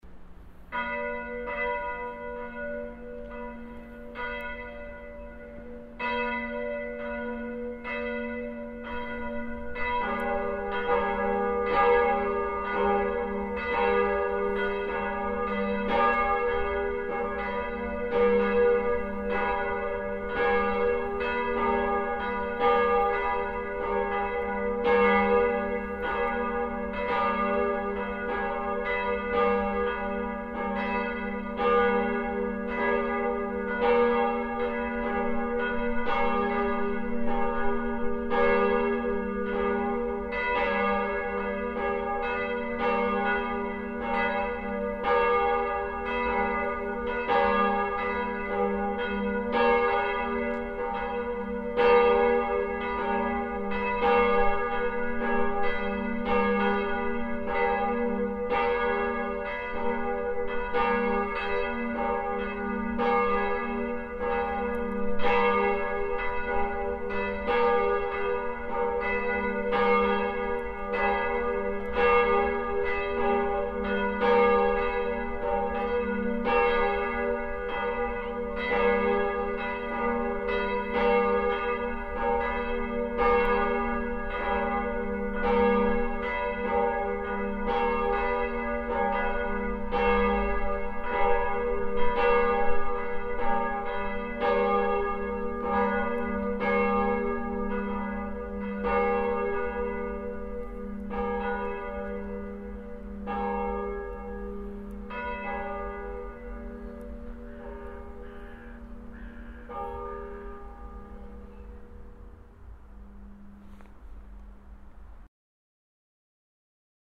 Da St. Lorenz vier verschiedene Glocken besitzt (nämlich mit den Nominalen, also gehörten Tönen, d1, f1, g1 und b1), ergeben sich daraus verschiedene Läut-Kombinationsmöglichkeiten, die je nach Anlass zum Tragen kommen und die in der Läuteordnung für St. Lorenz festgelegt sind.
10-Glocken-4-3.mp3